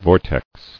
[vor·tex]